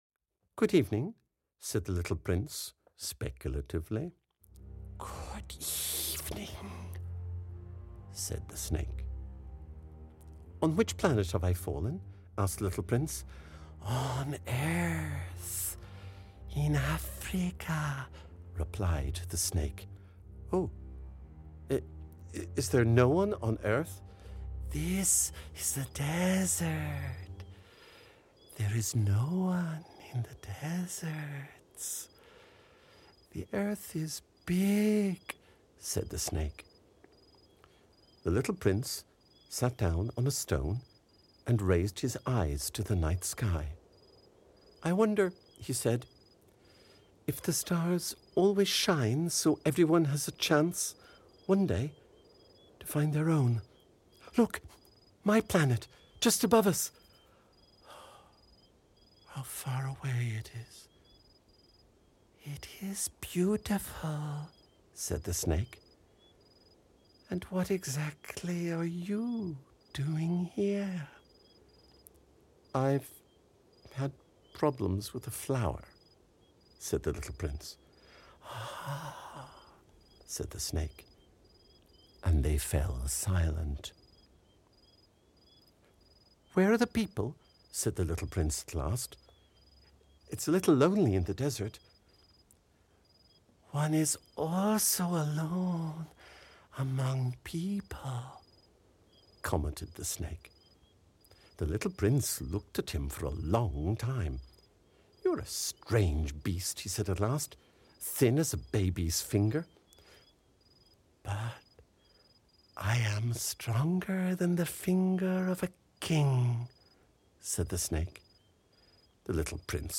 The Little Prince audiokniha
Audiobook The Little Prince written by Antoine de Saint-Exupéry.
Ukázka z knihy